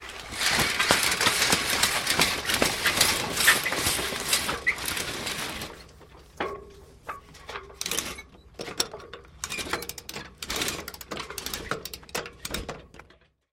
Погрузитесь в атмосферу морских путешествий с коллекцией звуков паруса: шелест натянутой ткани, ритмичный стук волн о борт, крики чаек.
Звук Сворачиваем парус (звук) (00:14)